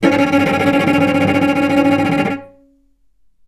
vc_trm-C#4-mf.aif